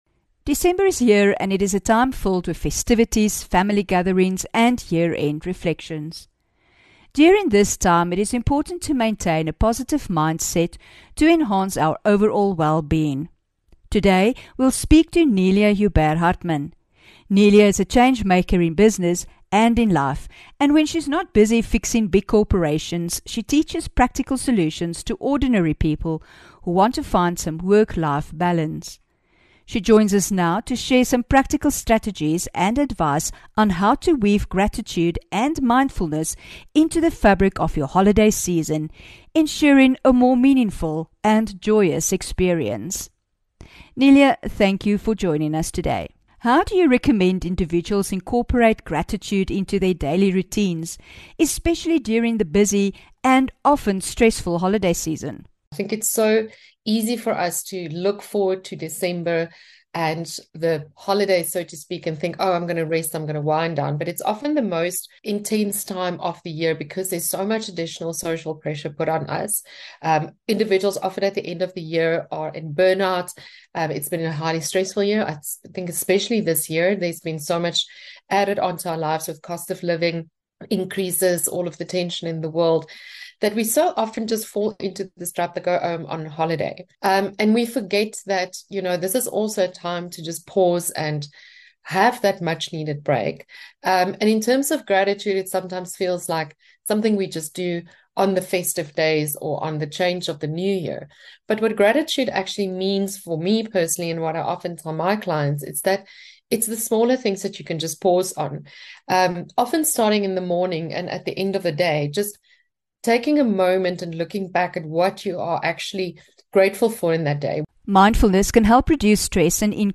5 Dec INTERVIEW